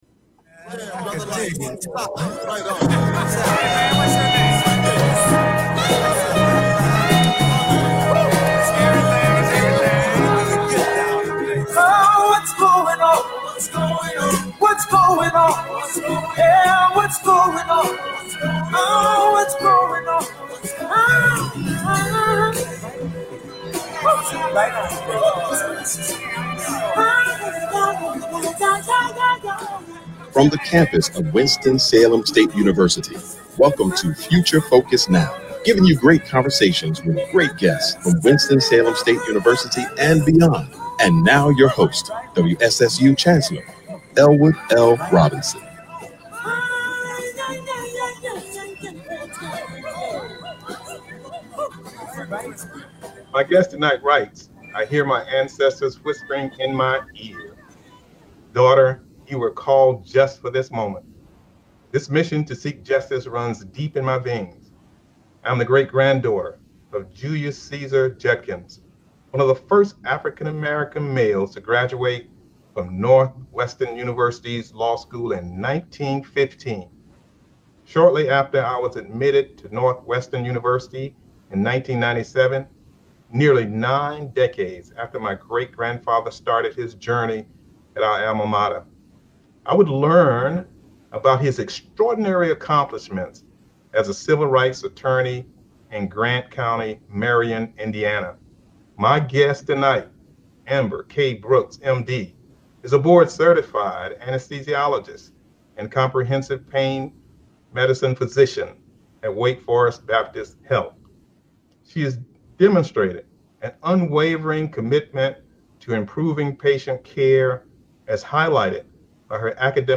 Future Focus is a one-hour public affairs talk show